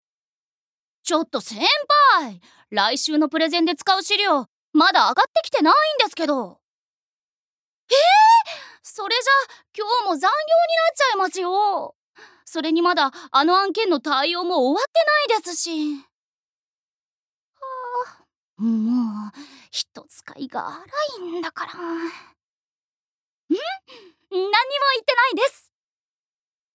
Voice Sample
セリフ１